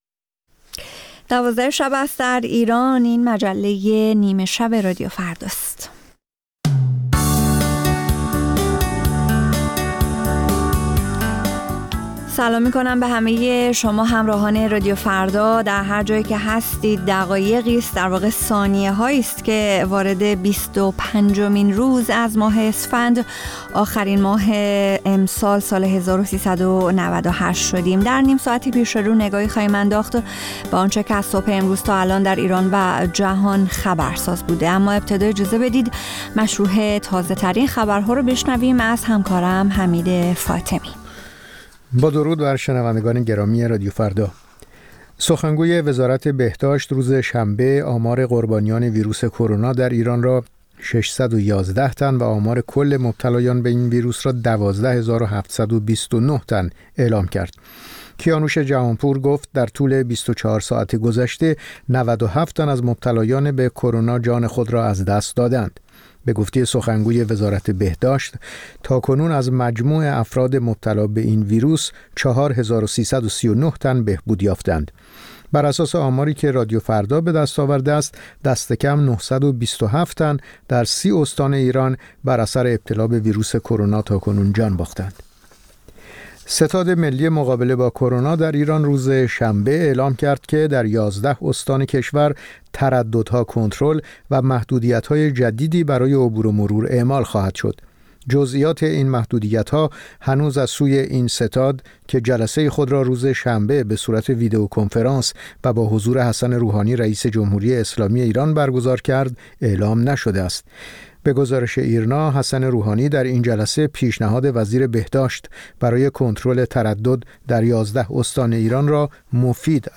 همچون هر روز، مجله نیمه‌شب رادیو فردا، تازه‌ترین خبرها و مهم‌ترین گزارش‌ها را به گوش شما می‌رساند.